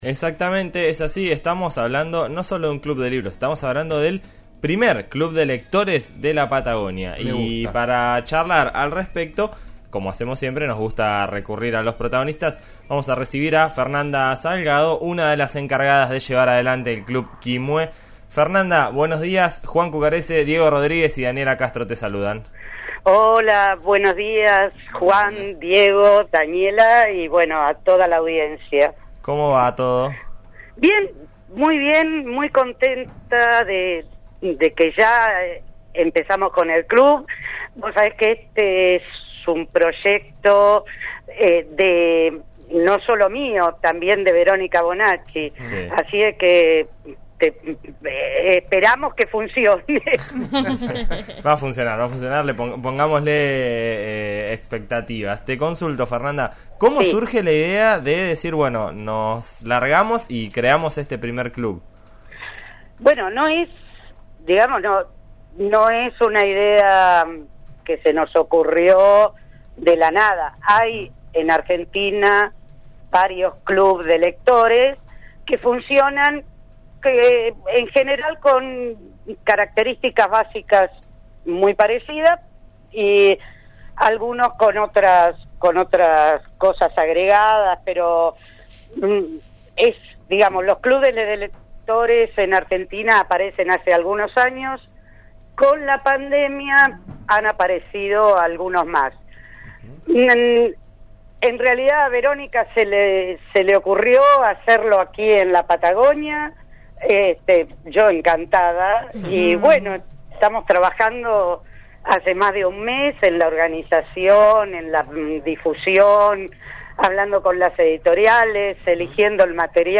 El equipo de En Eso Estamos (RÍO NEGRO RADIO - FM 89.3 en Neuquén) charló con